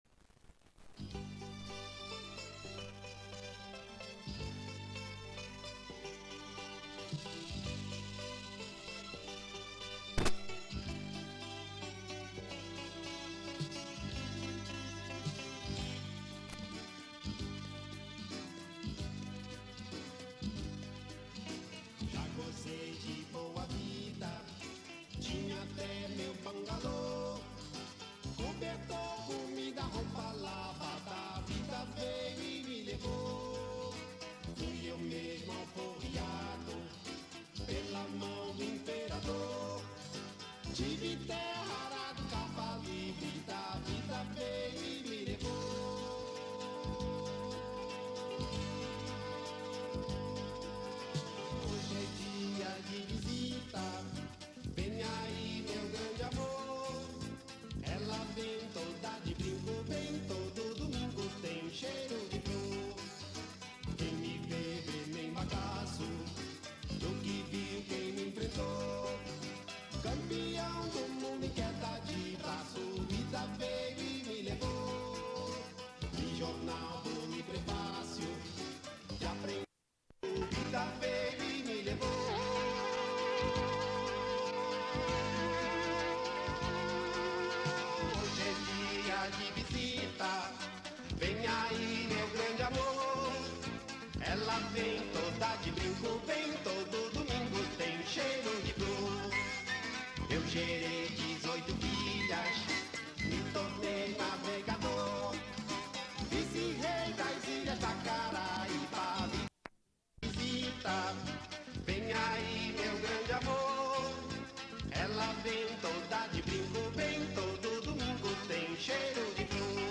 Type: Gravação musical